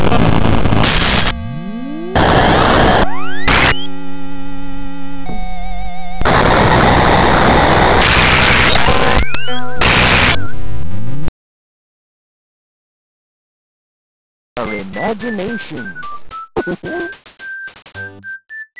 this is raw to the extent that i am loading various states into memory as the mood takes me